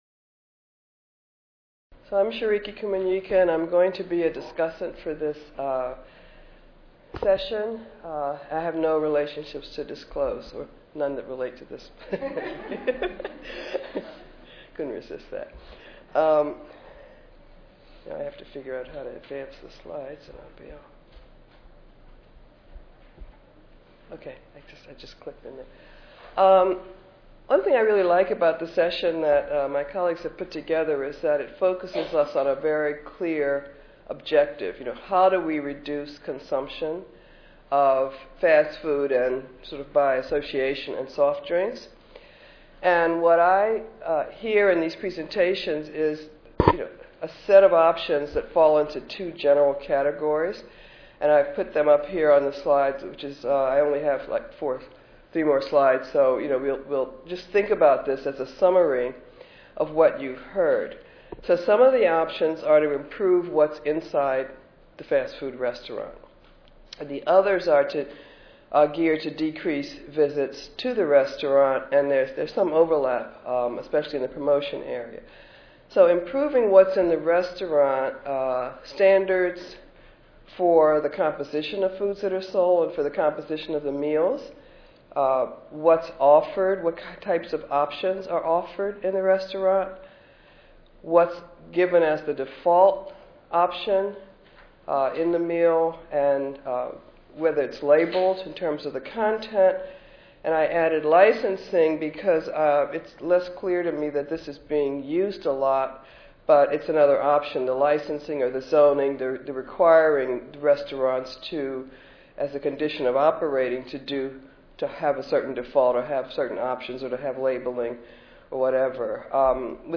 141st APHA Annual Meeting and Exposition (November 2 - November 6, 2013): Preventing overconsumption of fast food by young people: Strategies to improve fast food nutritional quality and reduce restaurant visits